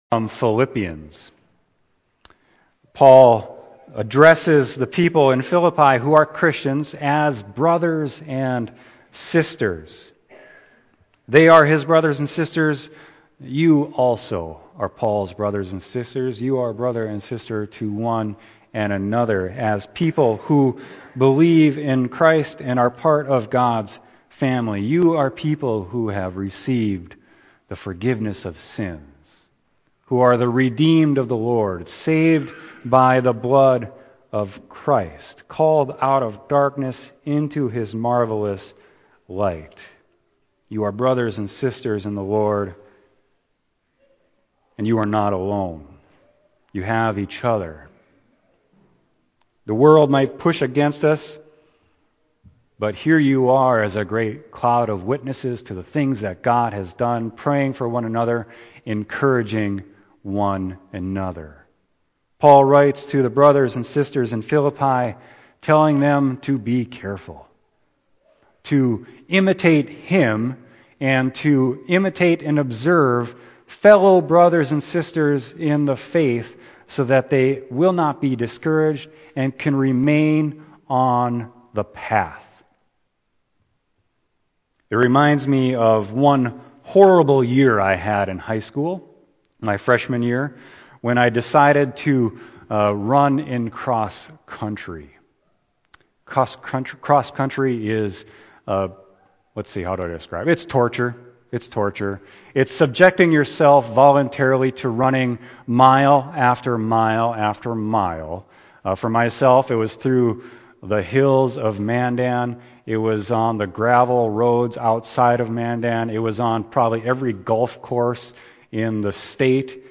Sermon: Philippians 3.17-4.1